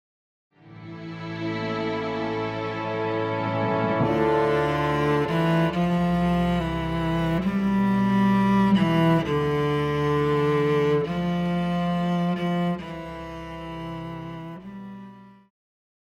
Pop
Cello
Band
Instrumental
World Music,Electronic Music
Only backing